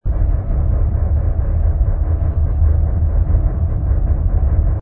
rumble_lifter.wav